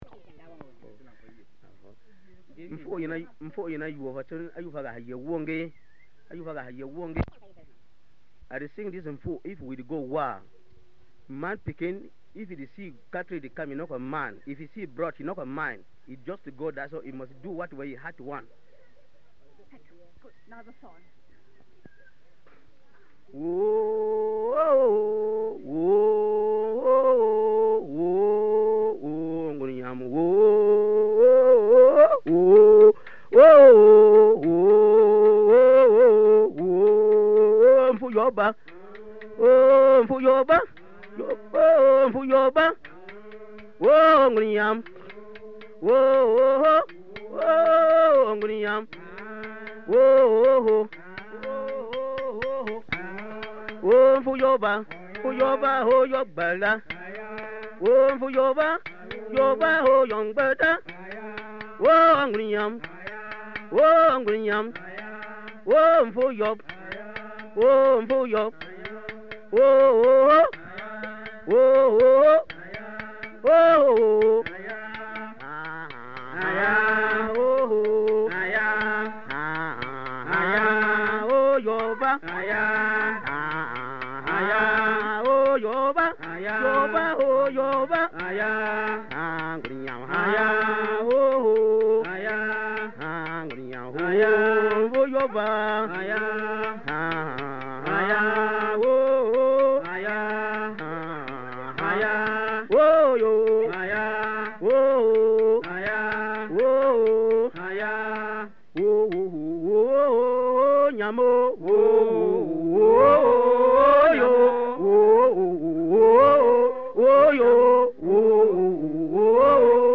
Bayang men's songs
From the sound collections of the Pitt Rivers Museum, University of Oxford, being from a collection of reel-to-reel tape recordings of songs and musical instruments